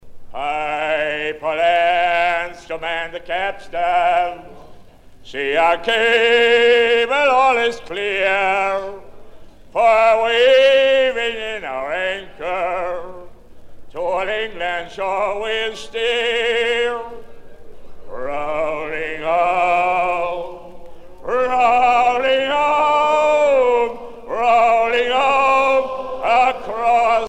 Mémoires et Patrimoines vivants - RaddO est une base de données d'archives iconographiques et sonores.
Chanson par un cap-hornier anglais